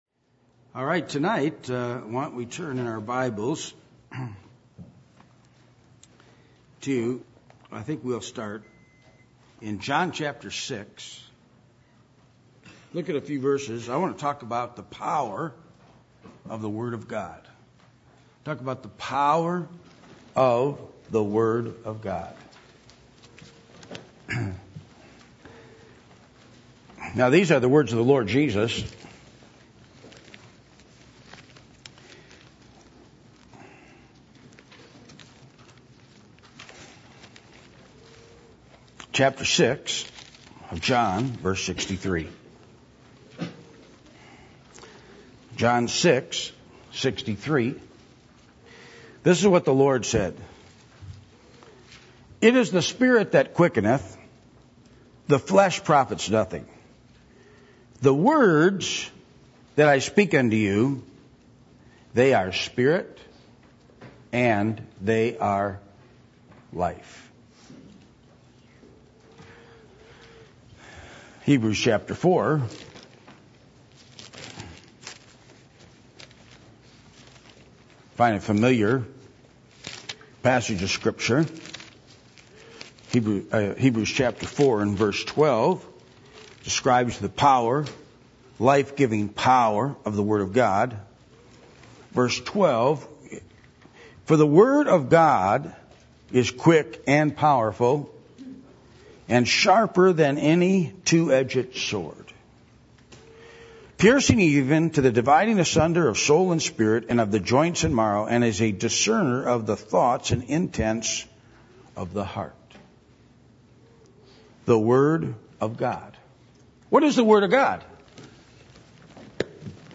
Service Type: Midweek Meeting